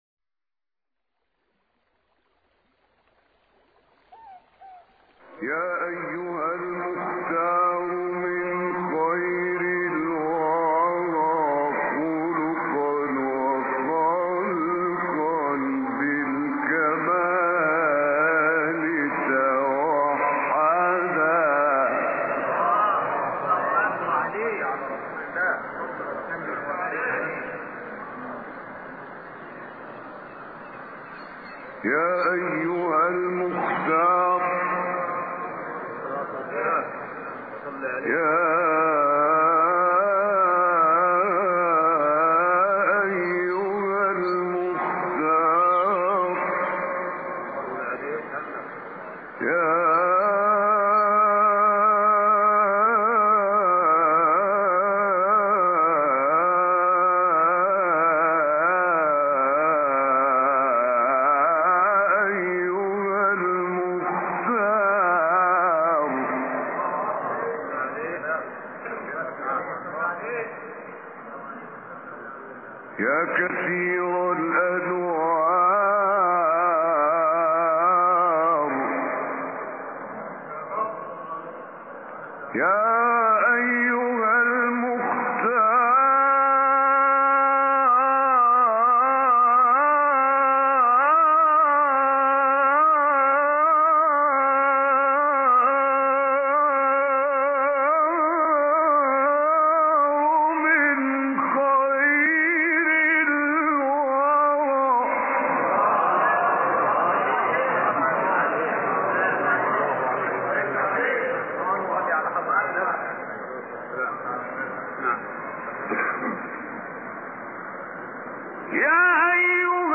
ابتهال